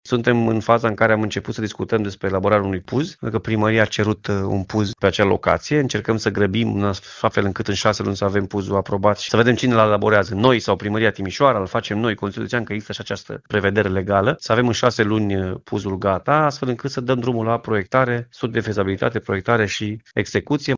Prima parcare va fi realizată în centru, iar proiectul este în faza de elaborare a Planului Urbanistic Zonal, spune președintele CJ Timiș, Alfred Simonis.